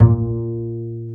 Index of /90_sSampleCDs/Roland - String Master Series/STR_Cb Pizzicato/STR_Cb Pizz 2